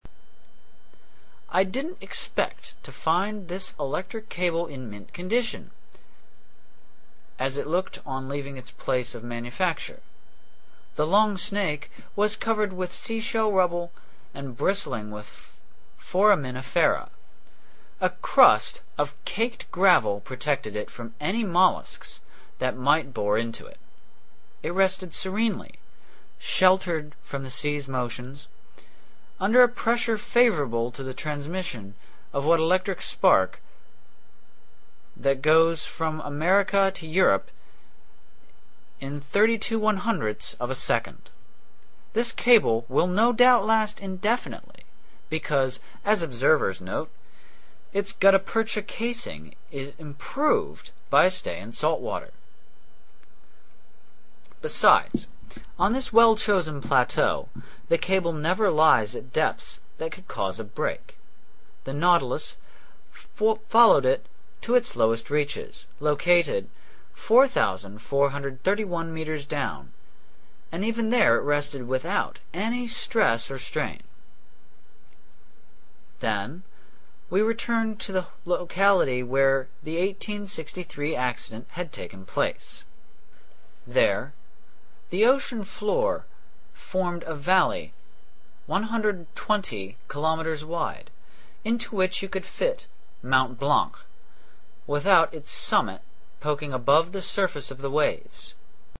在线英语听力室英语听书《海底两万里》第534期 第33章 北纬47.24度, 西经17.28度(9)的听力文件下载,《海底两万里》中英双语有声读物附MP3下载